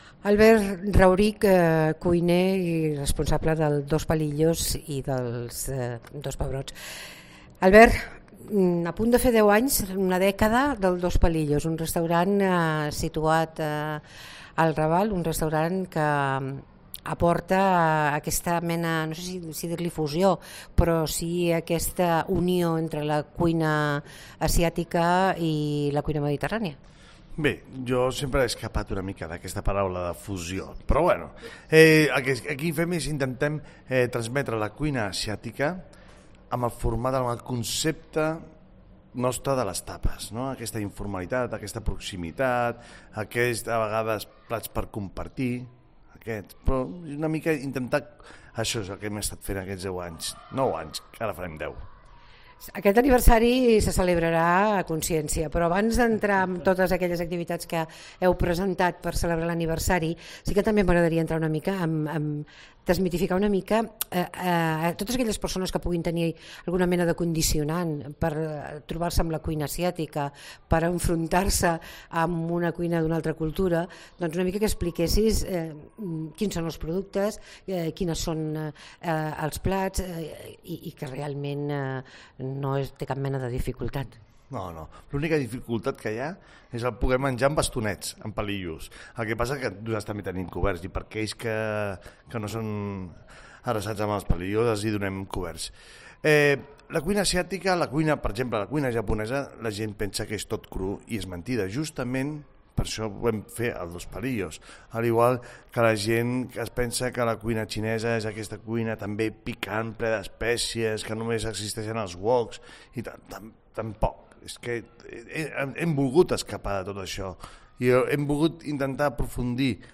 Entrevistem l'Albert Raurich per la celebració del 10è aniversai del restaurant Dos Palillos